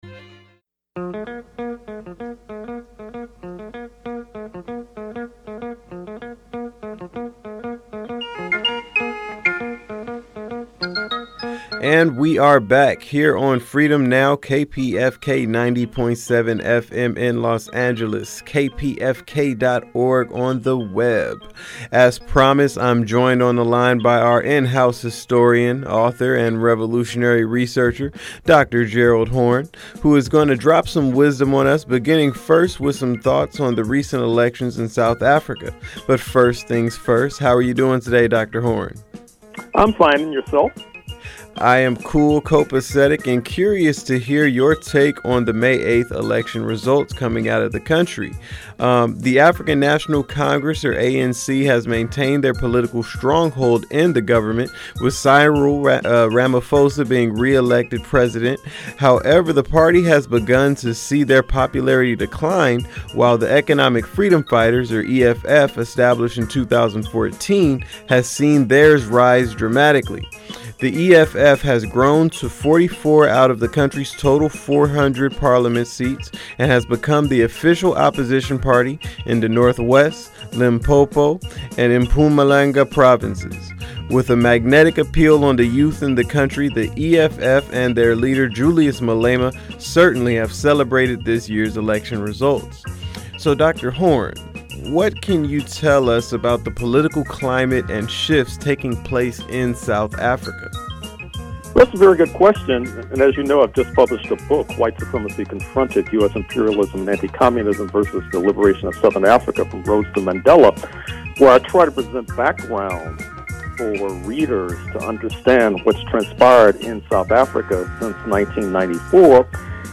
Gerald Horne talks to KPFK’s Freedom Now about South Africa and “Jazz and Justice”